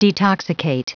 Prononciation du mot detoxicate en anglais (fichier audio)
Prononciation du mot : detoxicate